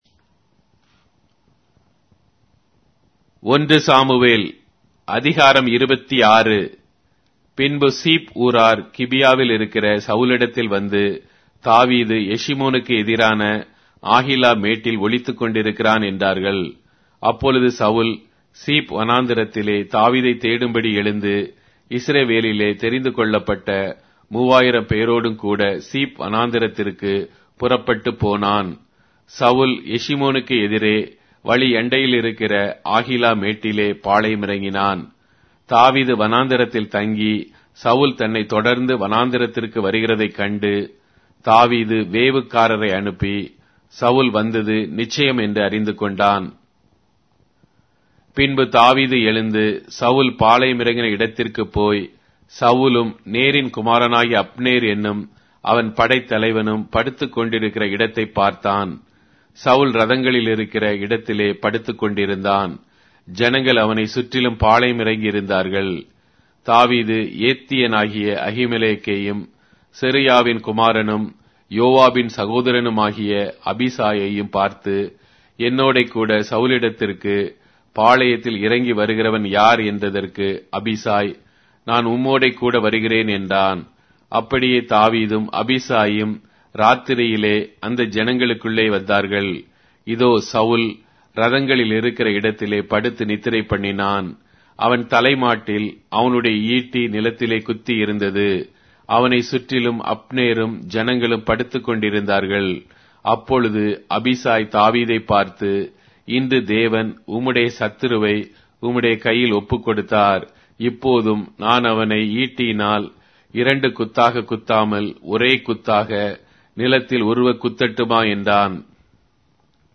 Tamil Audio Bible - 1-Samuel 24 in Irvml bible version